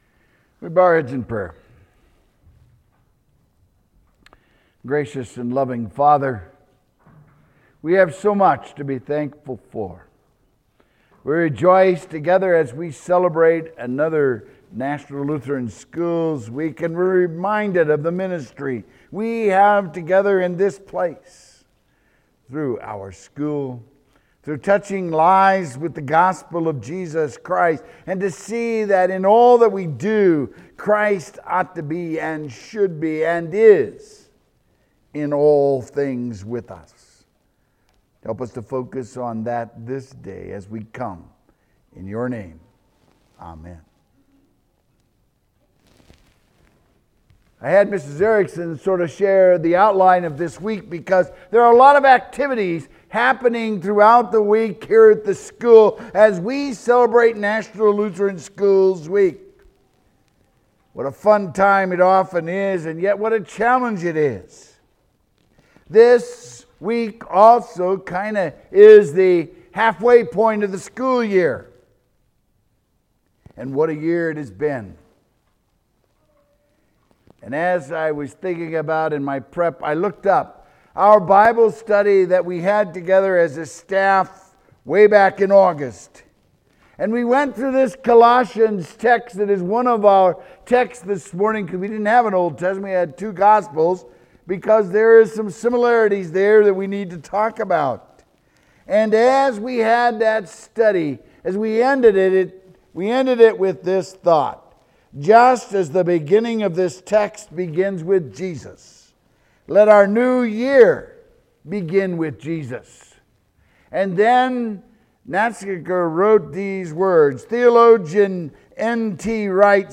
Christ In All Things Sermon January 23, 2022